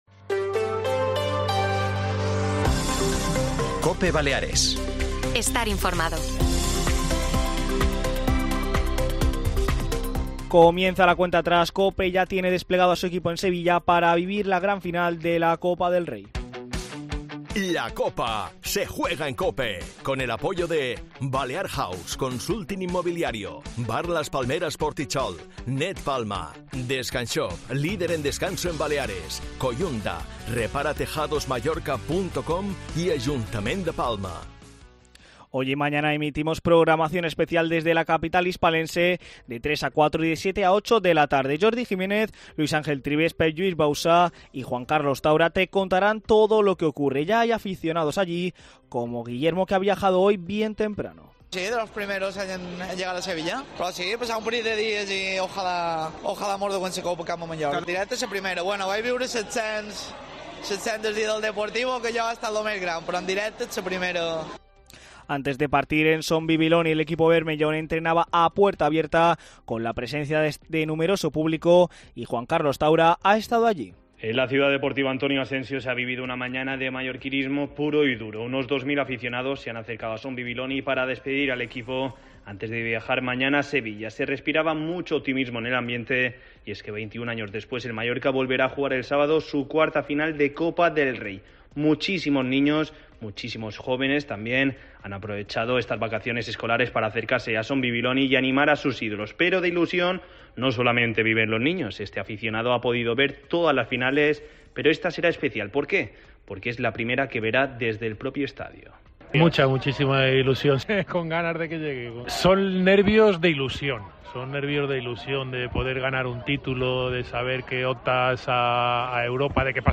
Escuchamos a los aficionados del RCD Mallorca en como será su desplazamiento a Sevilla y predicción para el partido. Mercadona ofrece más de 100 empleos para vernao en Baleares